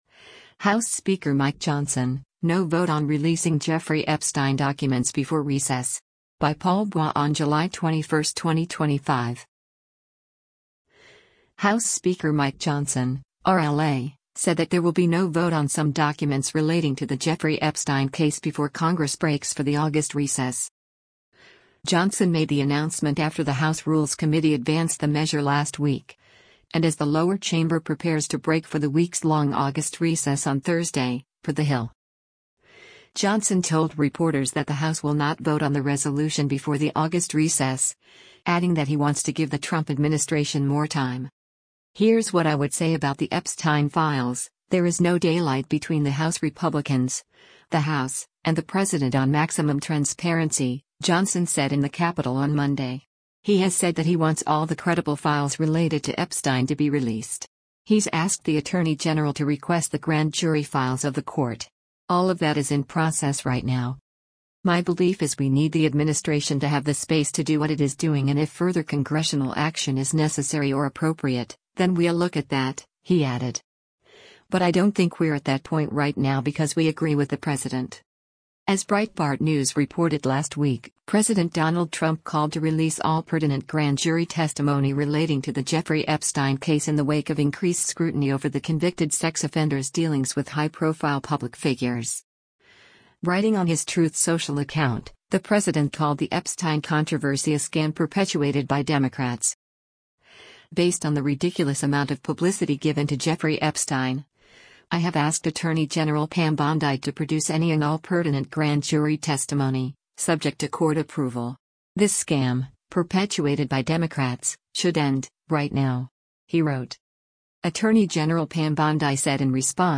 WASHINGTON, DC - JULY 21: U.S. Speaker of the House Mike Johnson (R-LA) speaks to reporter